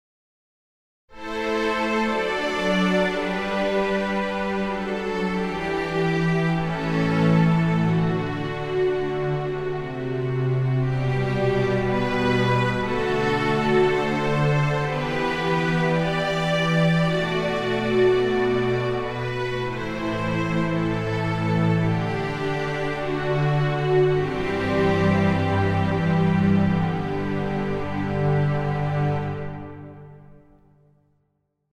Orchestration